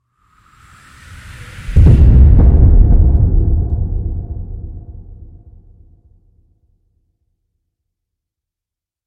Звуки бомбёжек
Глухой грохот падающей бомбы при авиаударе и последующий взрыв